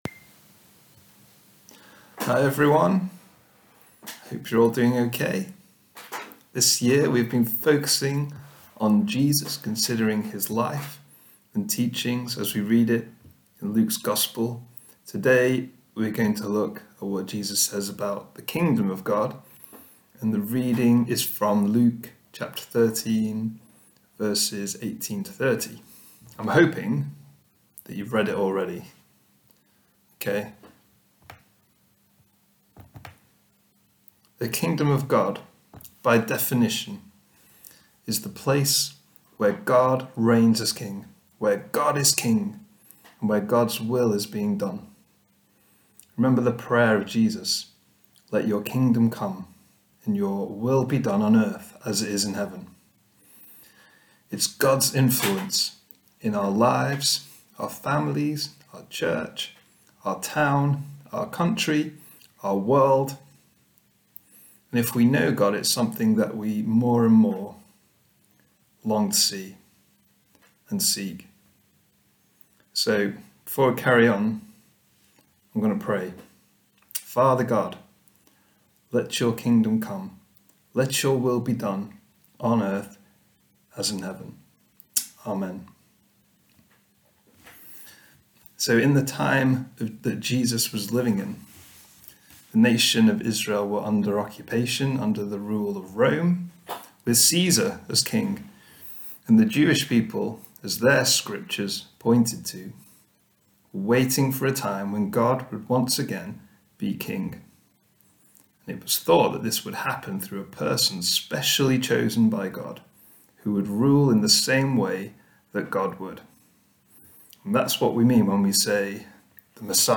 Talks - WSCF